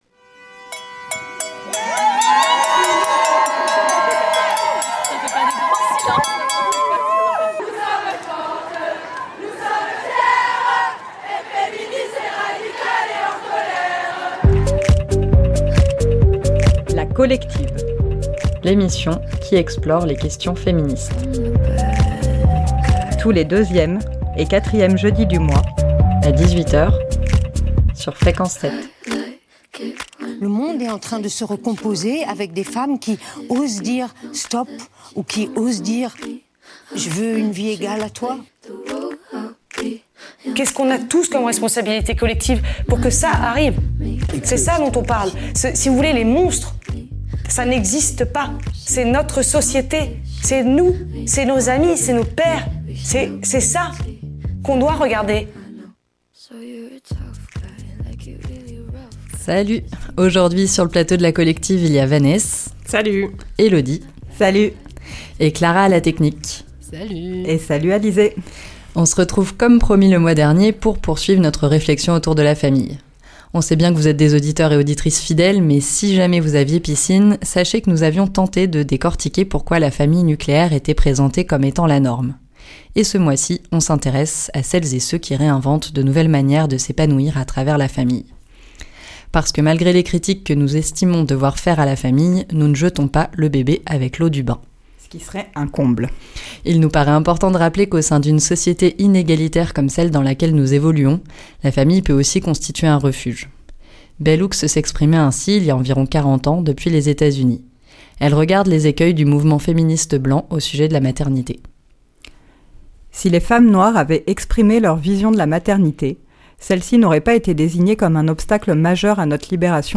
Pour tenter de s'y retrouver, nous vous proposons une émission mensuelle qui, sans prétention, regarde, interroge et explore le monde au prisme du genre. Des témoignages, des entretiens, des reportages, des lectures et de la musique pour prendre conscience que les dominations sont multiples et que le féminisme peut transformer notre regard sur le monde et nous-même.